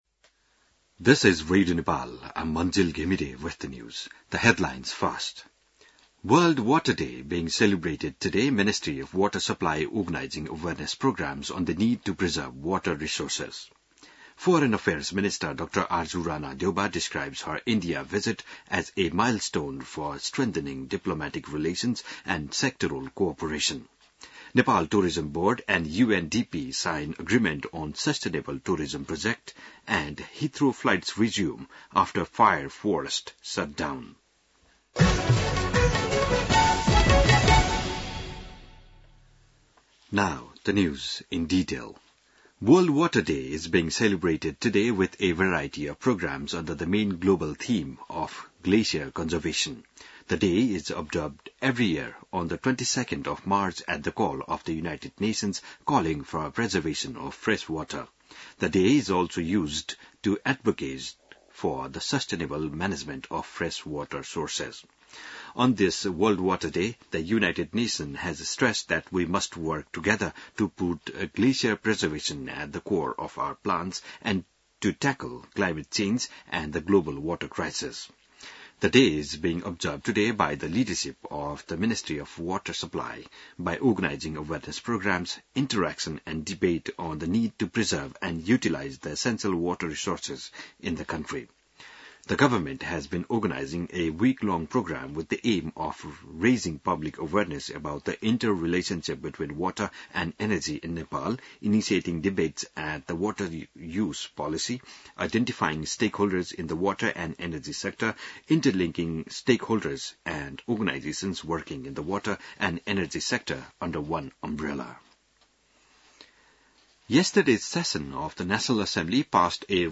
बिहान ८ बजेको अङ्ग्रेजी समाचार : ९ चैत , २०८१